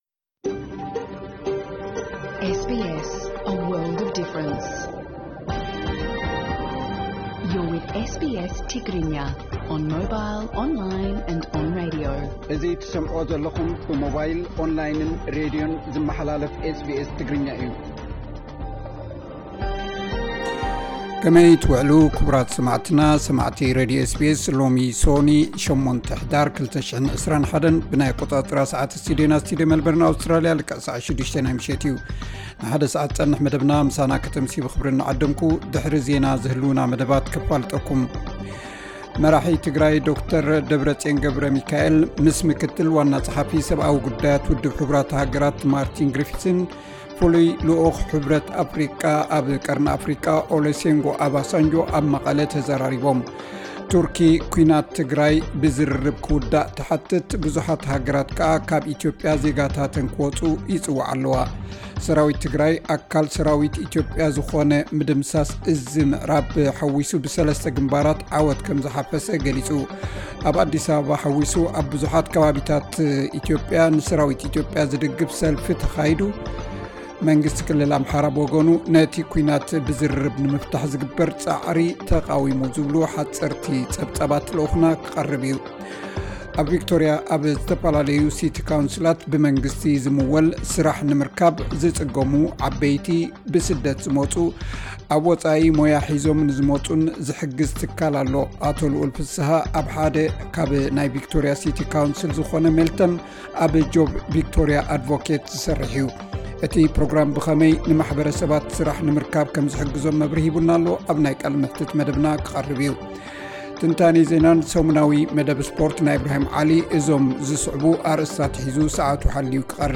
ዕለታዊ ዜና SBS ትግርኛ (8 ሕዳር 2021)